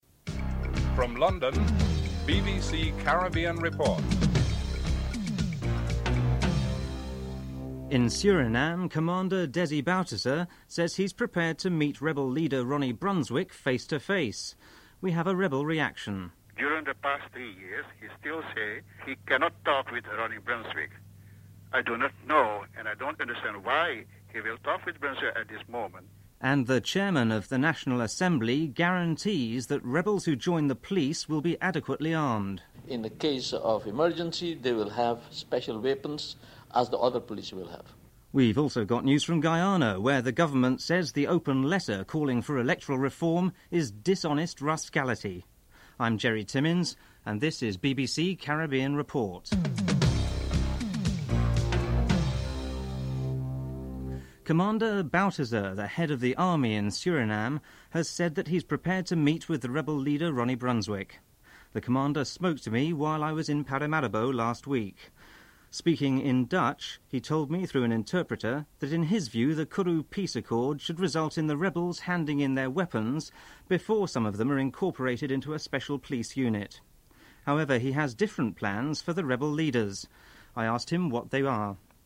1. Headlines (00:00-00:51)
3. Financial news (09:10-09:58)